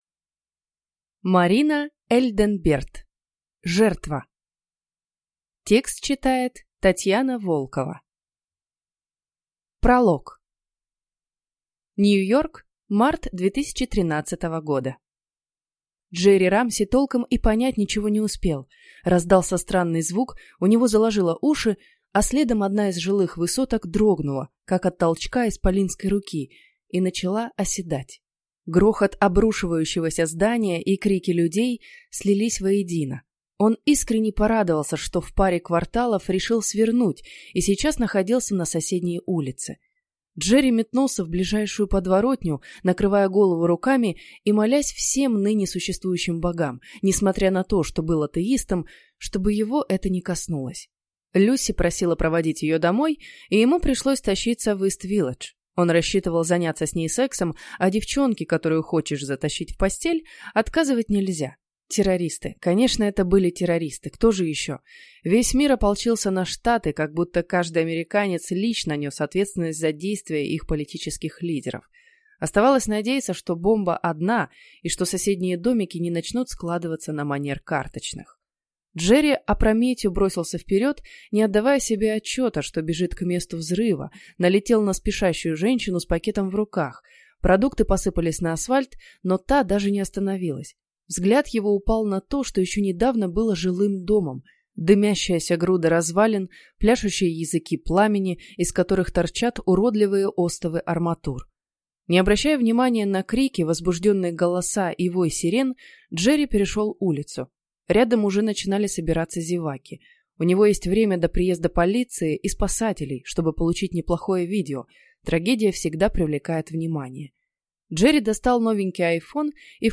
Аудиокнига Жертва | Библиотека аудиокниг